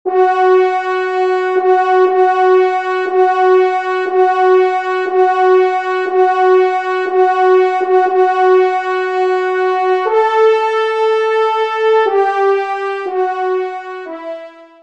Genre :  Musique Religieuse pour Trois Trompes ou Cors
Pupitre 1°Trompe